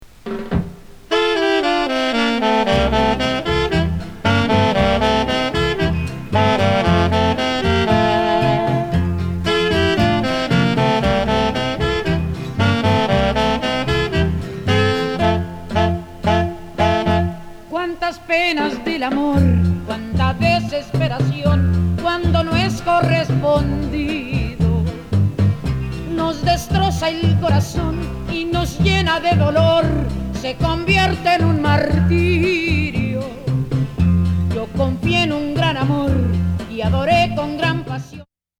シンプルなバンド演奏をバックに歌う、SP時代から
ジックリ聴きたい、巻舌極ランチェーラの１枚！！